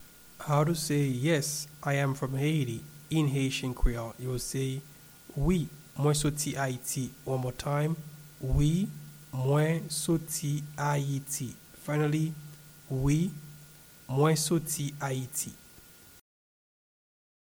Yes-I-am-from-Haiti-in-Haitian-Creole-Wi-mwen-soti-Ayiti-pronunciation.mp3